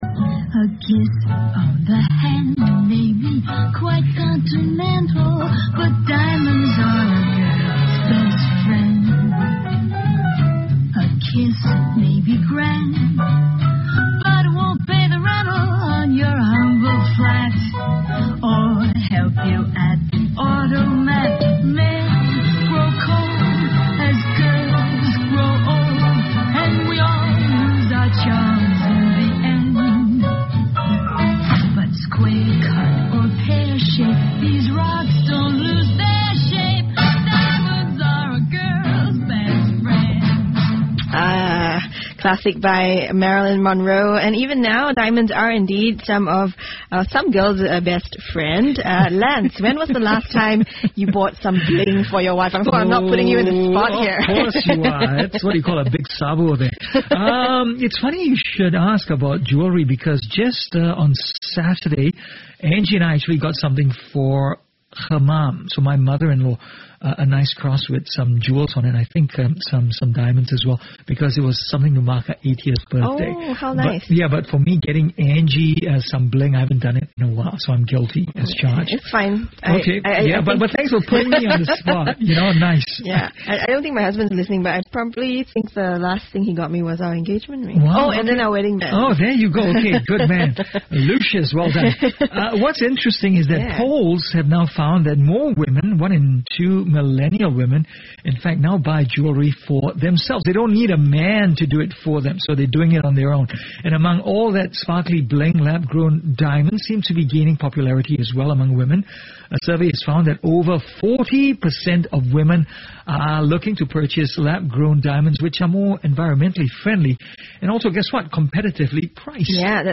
Click play to listen to the CNA interview.